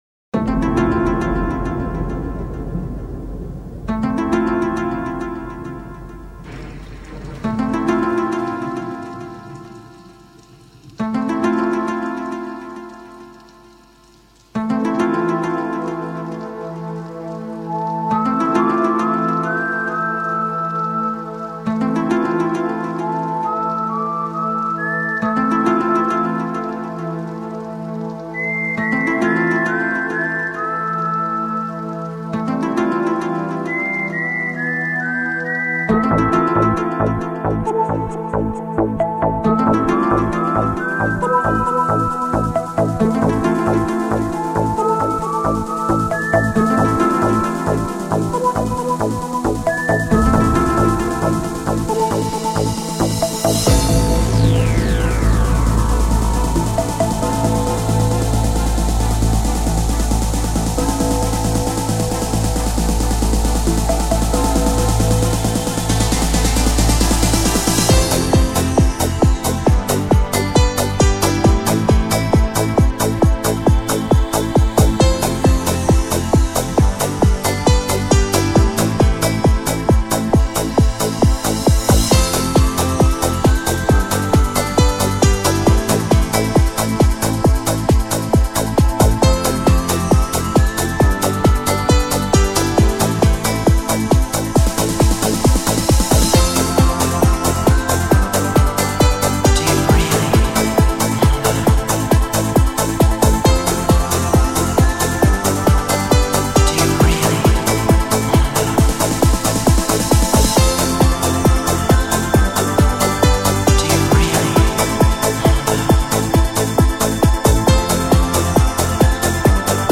Дискотечно-танцевальный ремикс